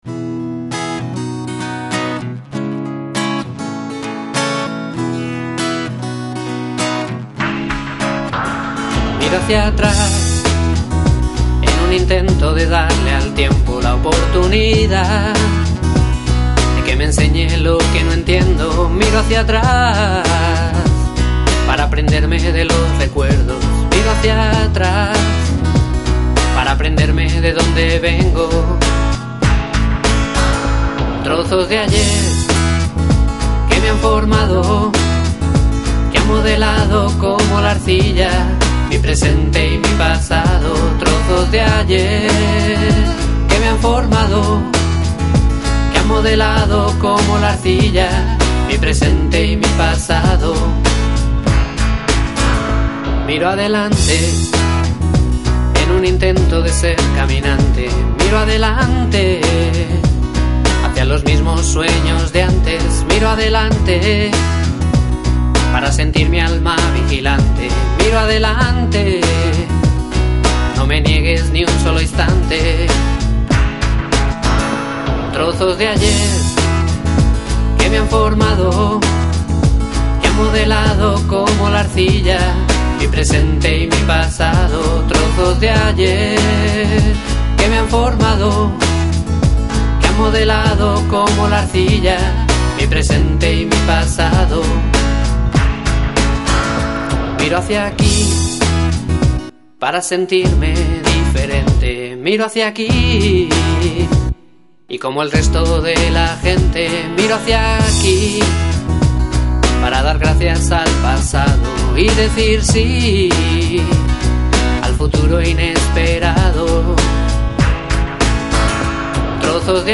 Cantautor madrileño